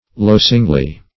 losingly - definition of losingly - synonyms, pronunciation, spelling from Free Dictionary Search Result for " losingly" : The Collaborative International Dictionary of English v.0.48: Losingly \Los"ing*ly\, adv. In a manner to incur loss.